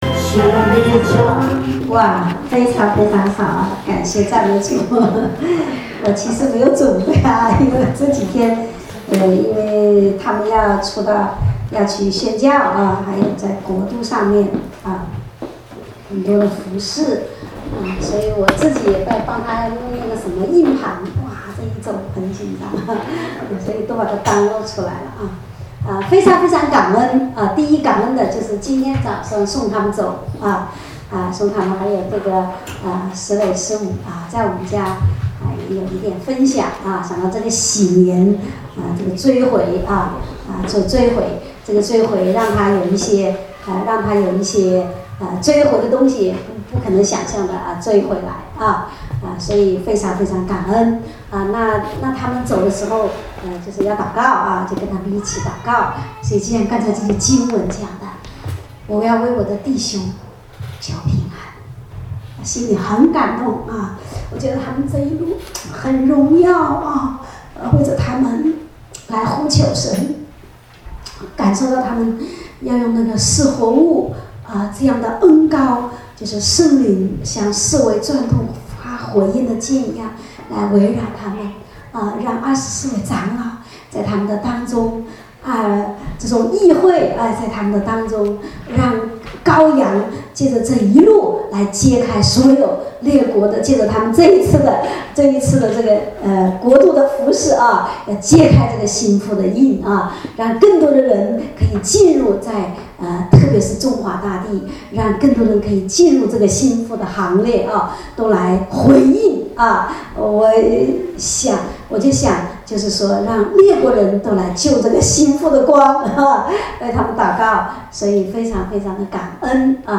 主日恩膏聚会录音（2016-02-07）